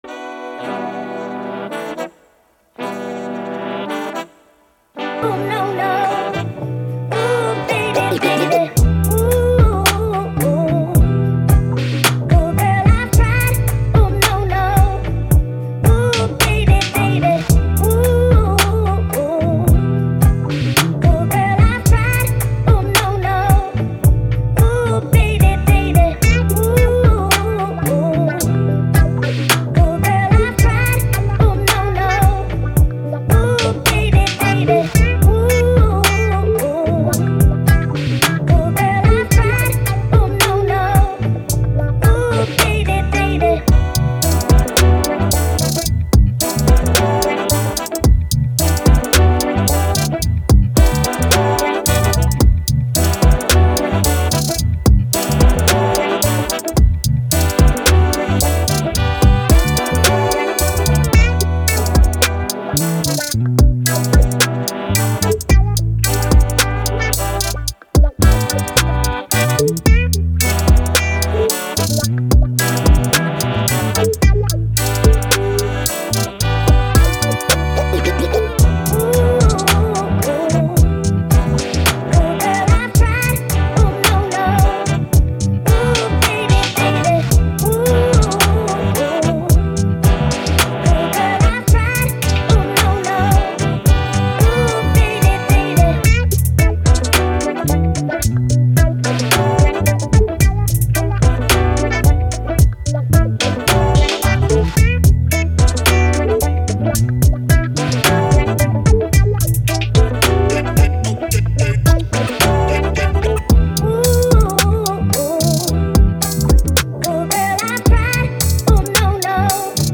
Hip Hop, Funky, Vibe